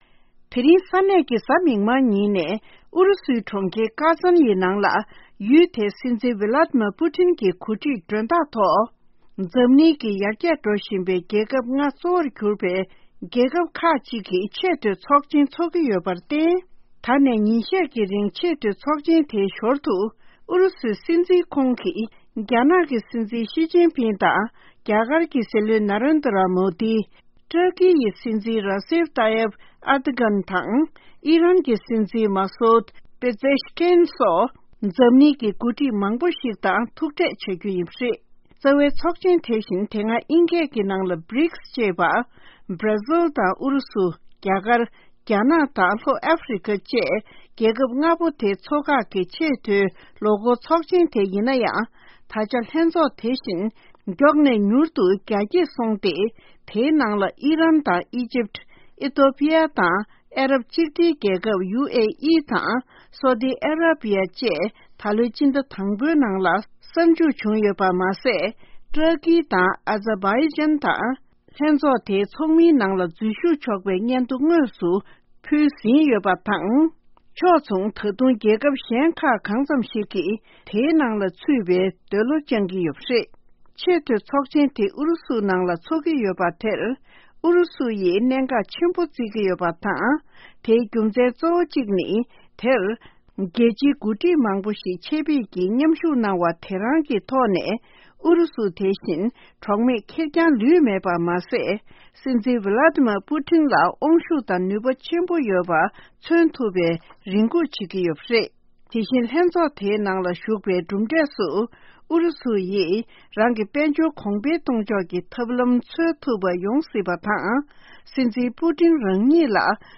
ཕྱོགས་བསྒྲིགས་དང་སྙན་སྒྲོན་ཞུ་ཡི་རེད།